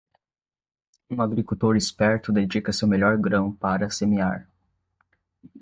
Pronounced as (IPA)
/ˈɡɾɐ̃w̃/